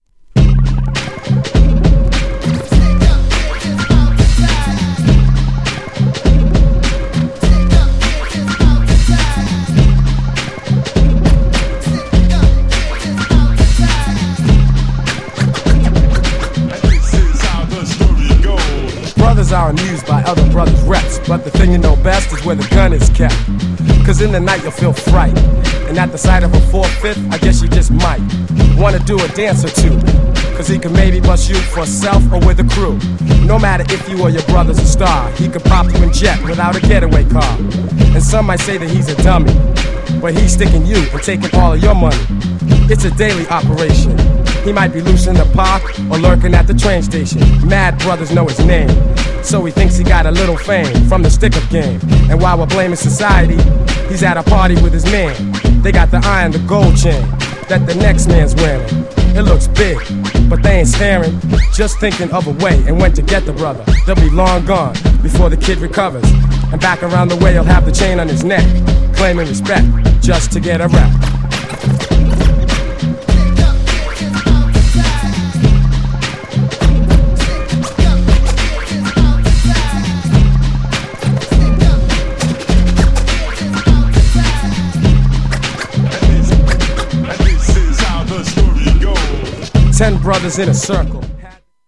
B面にも人気のミドルクラシック!!
GENRE Hip Hop
BPM 96〜100BPM
featに女性ボーカル
# フックでR&Bなコーラスがイイ!